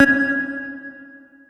key-press-3.wav